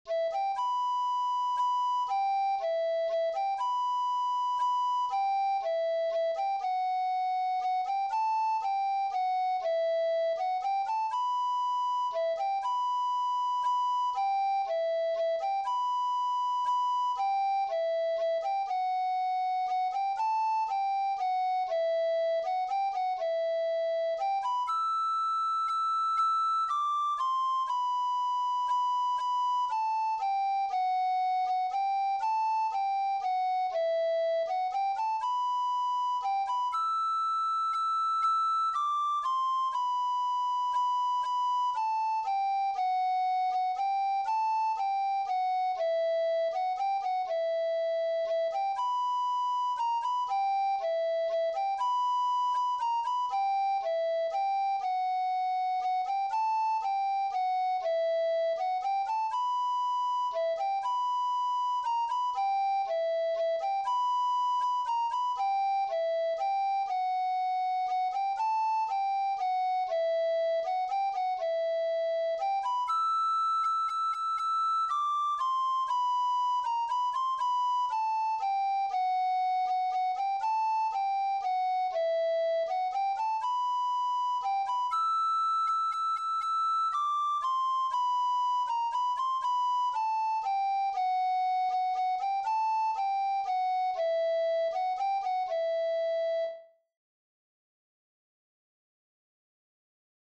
Emma-Waltz-from-Finland.mp3